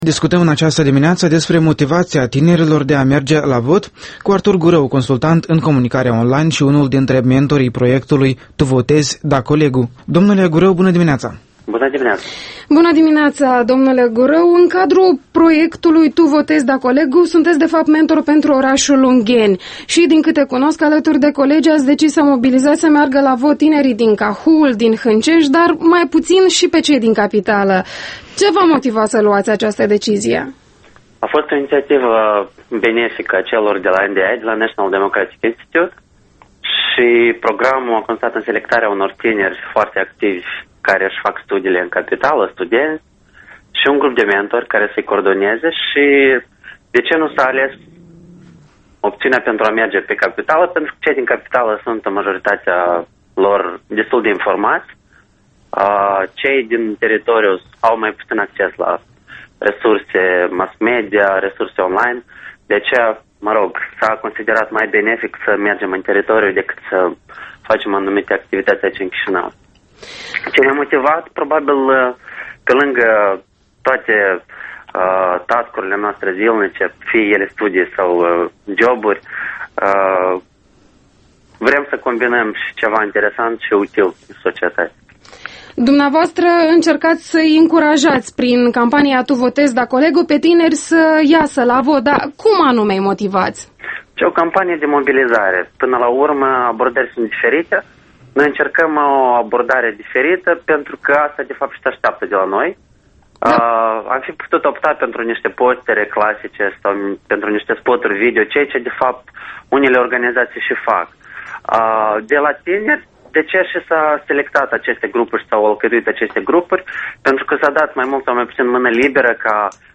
Interviul matinal EL: